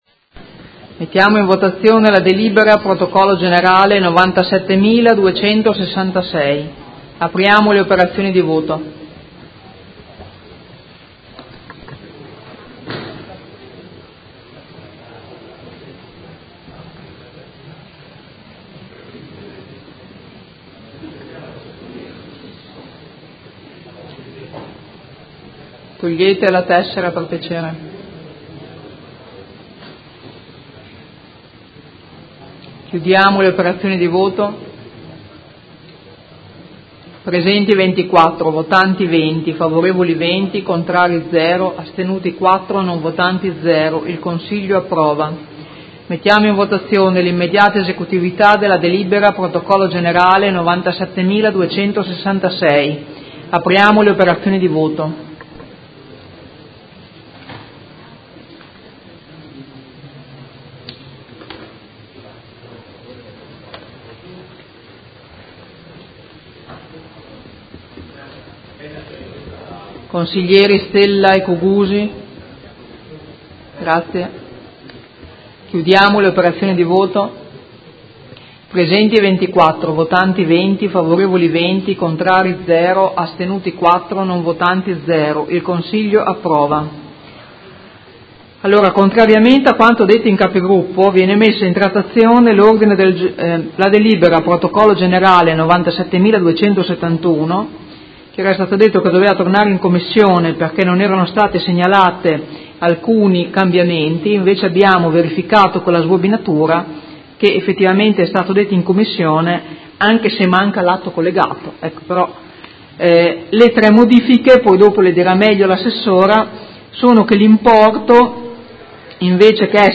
Presidentessa — Sito Audio Consiglio Comunale